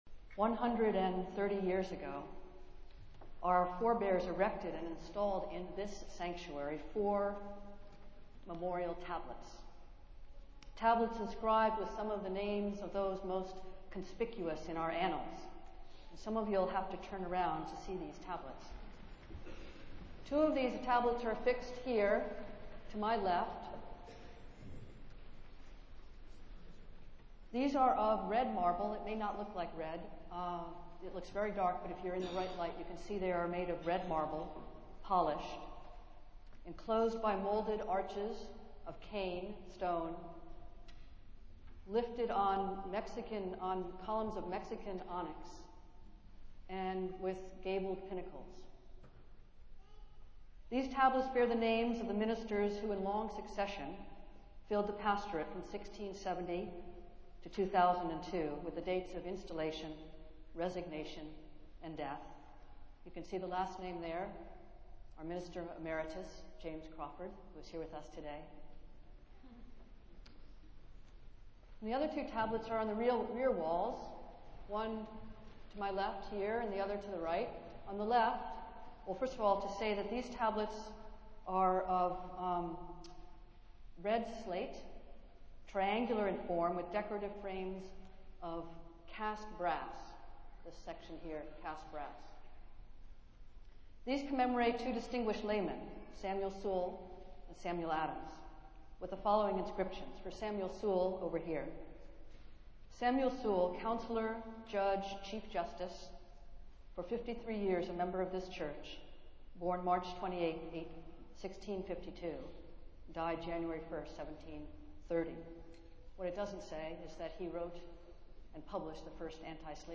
Festival Worship - 344th Annual Meeting Sunday